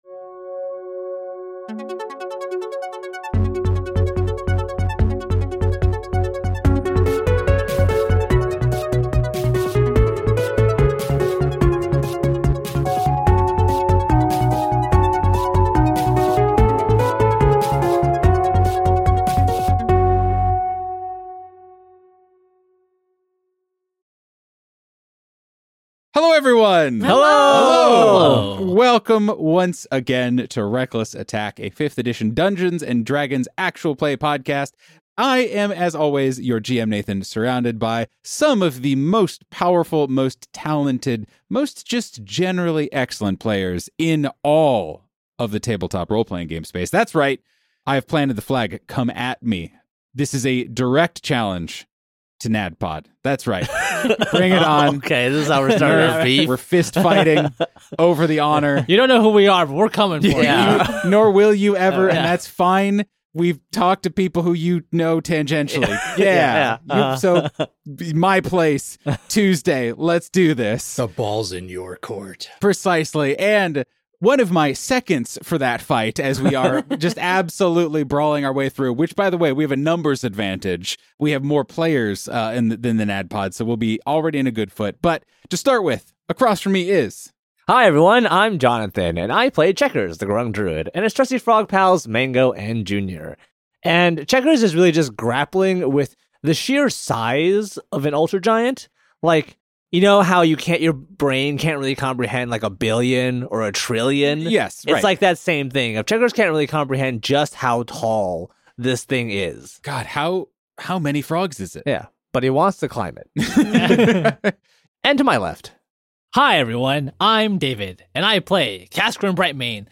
Reckless Attack is a Dungeons and Dragons 5th Edition Real Play podcast, hosted by a group of cheery, diverse, regular folks in Chicago who love their game and want to share it with you.